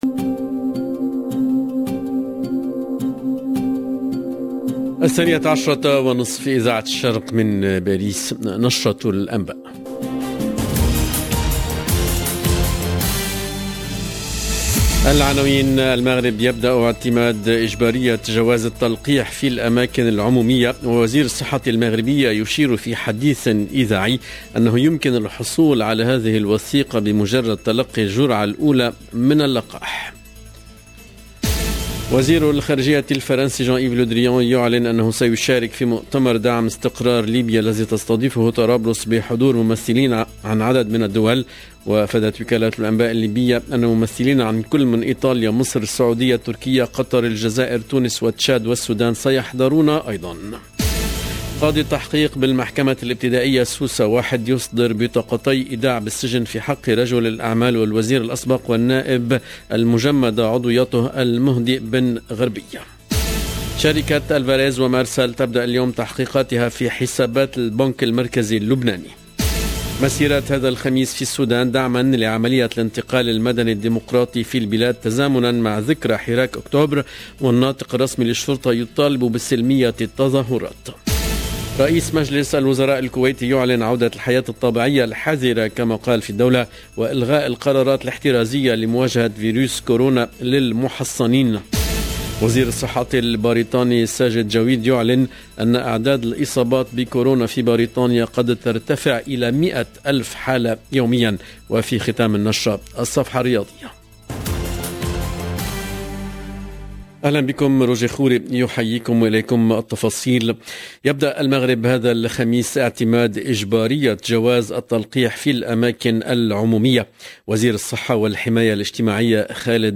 LE JOURNAL DE 12H30 EN LANGUE ARABE DU 21/10/21